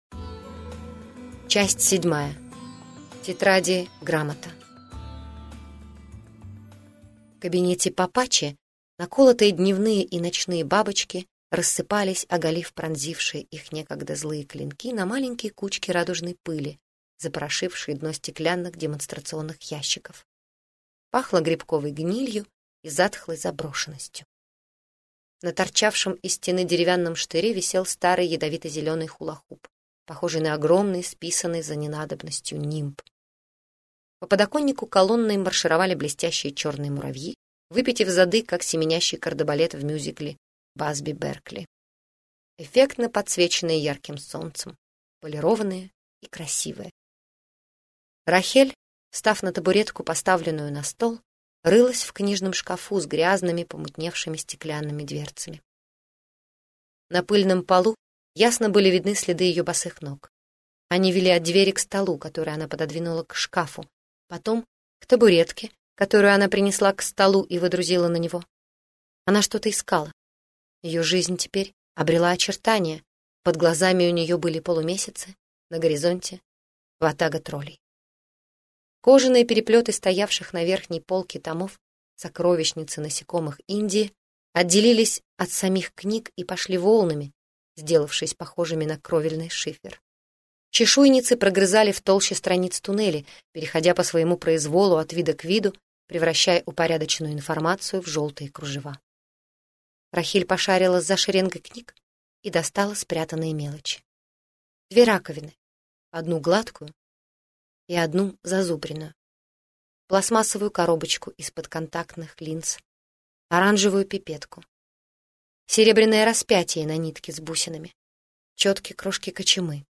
Аудиокнига Бог Мелочей - купить, скачать и слушать онлайн | КнигоПоиск